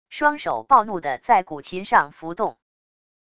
双手暴怒的在古琴上拂动wav音频